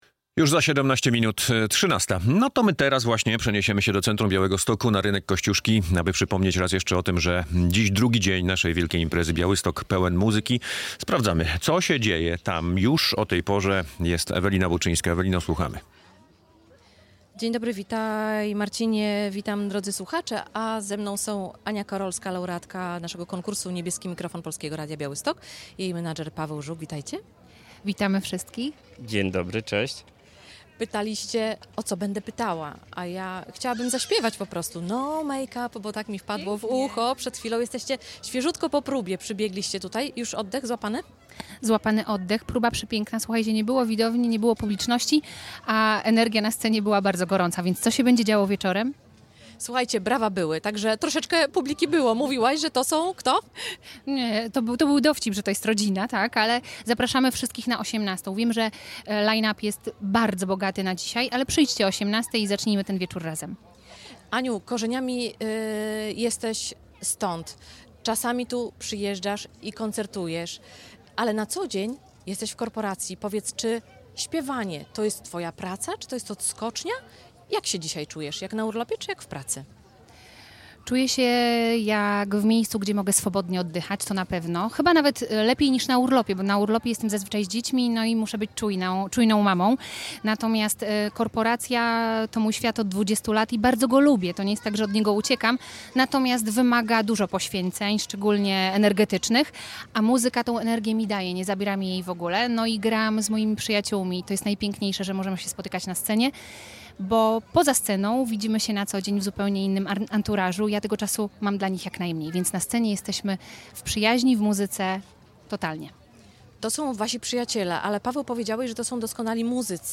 Przed wieczornym koncertem